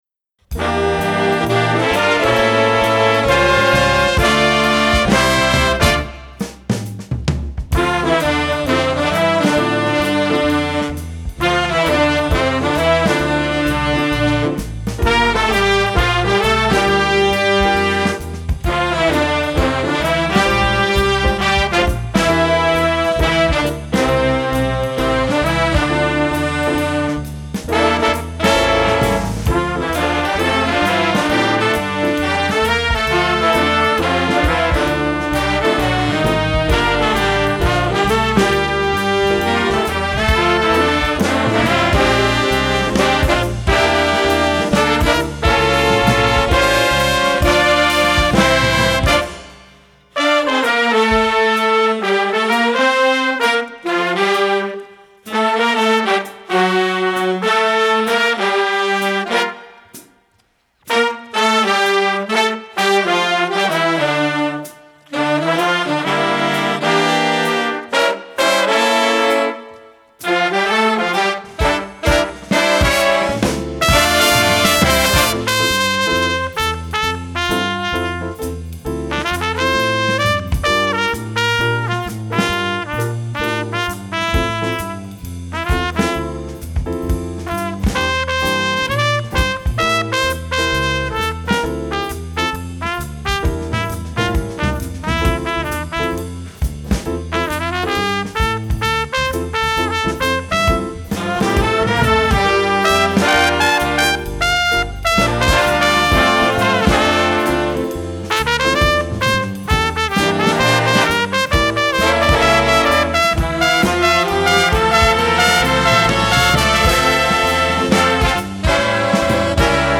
Voicing: Flex Jazz